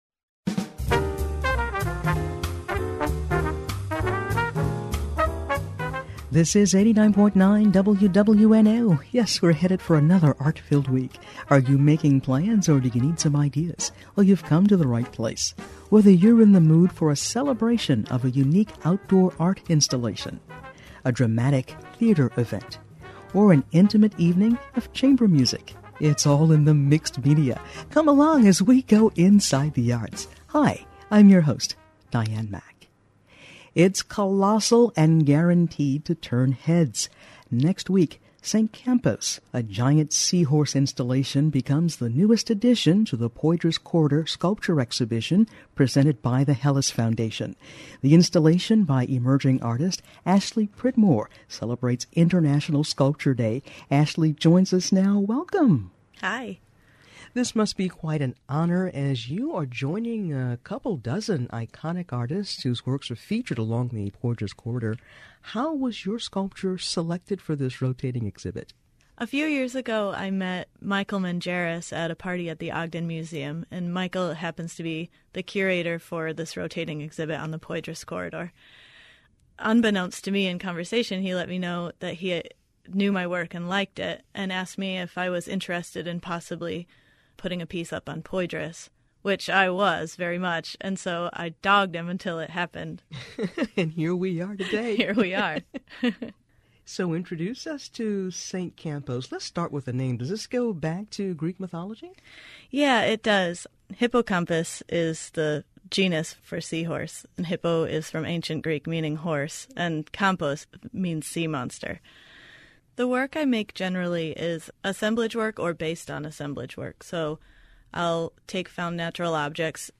Read additional text and listen to the interview on the WWNO-FM website .